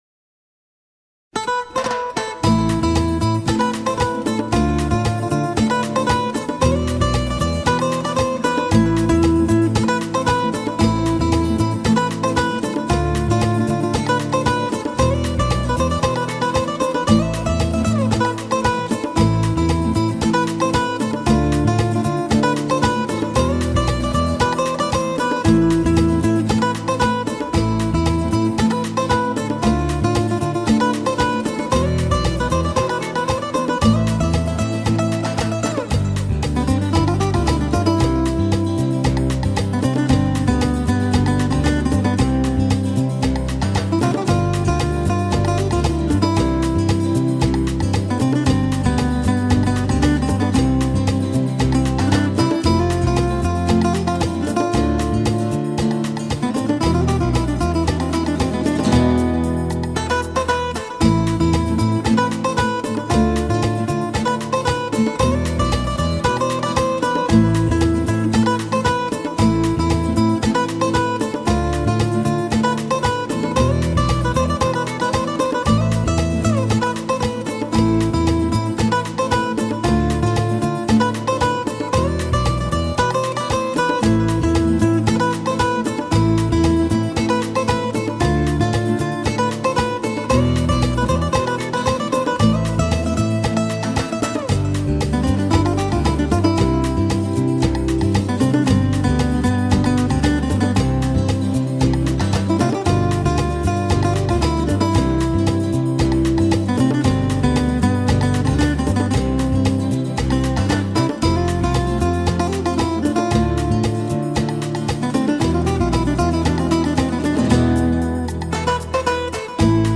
Latin Guitar
Acoustic Guitar